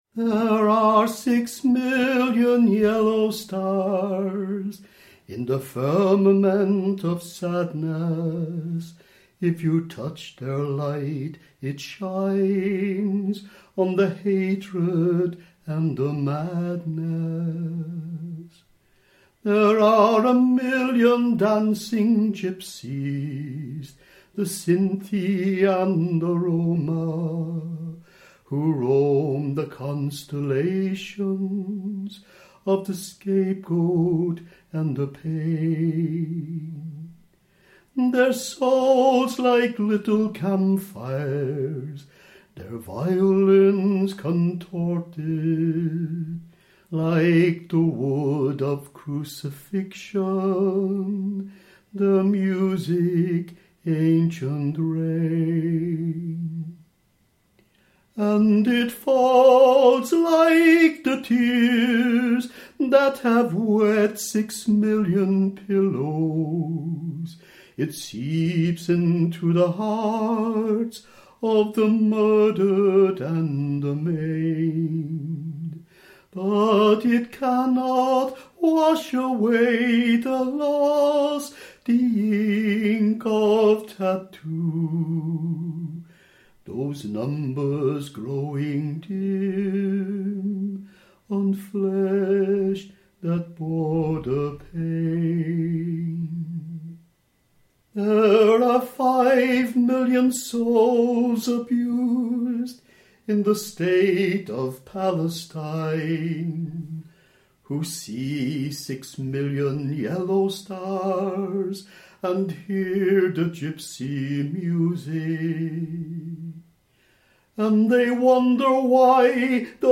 Six Million Yellow Stars ( — C Major)